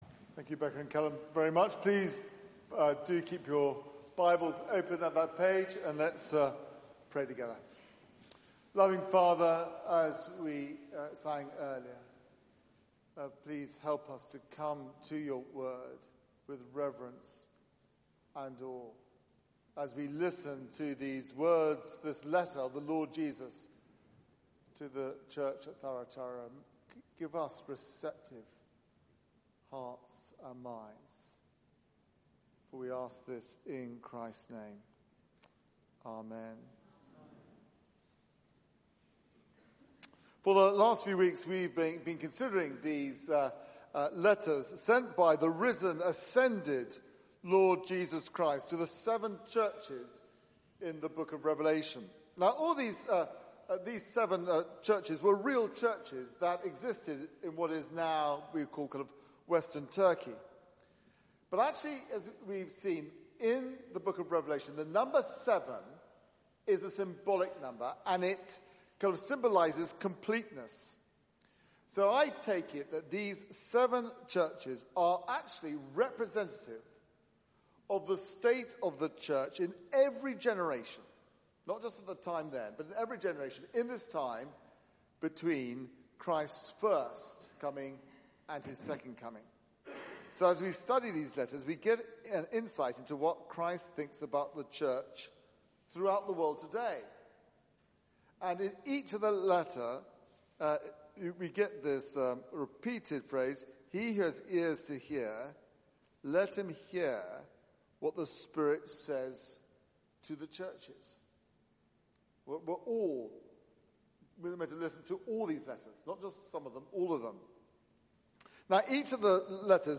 Media for 6:30pm Service on Sun 26th May 2019 18:30 Speaker
Series: The Lamb Wins Theme: The Risen Christ demands Repentance Sermon